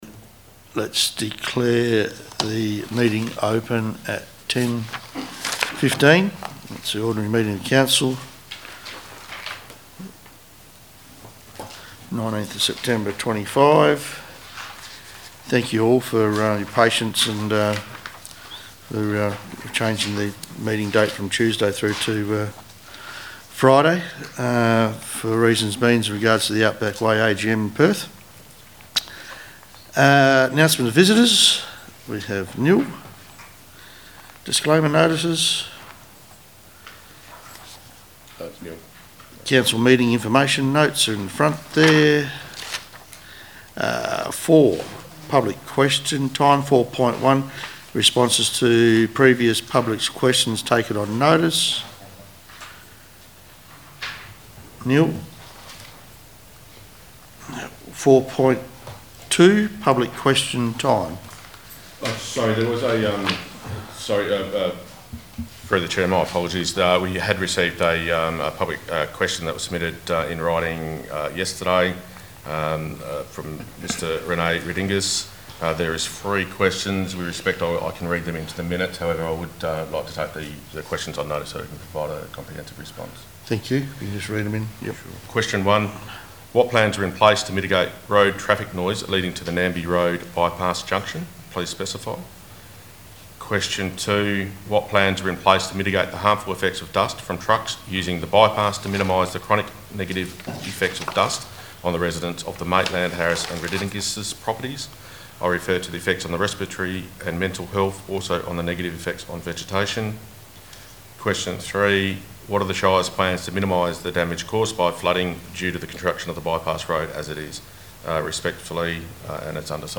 Ordinary Council Meeting - 19th September, 2025 » Shire of Leonora